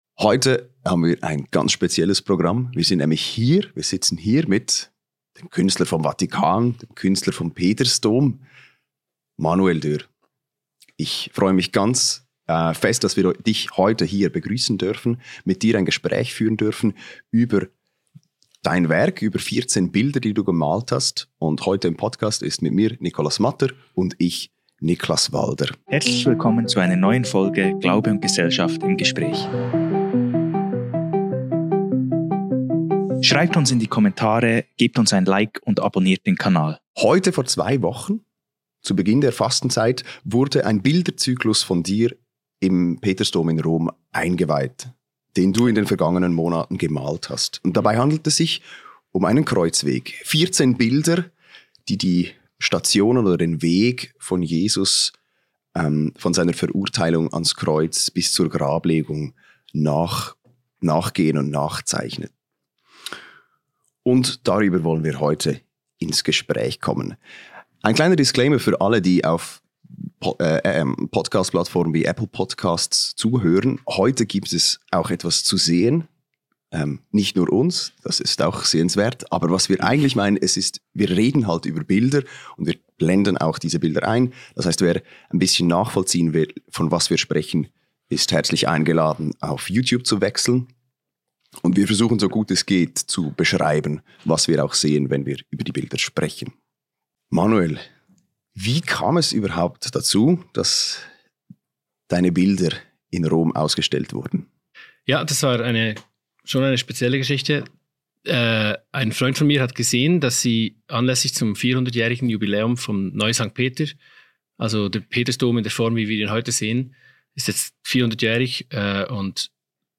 Malen für den Papst - Gespräch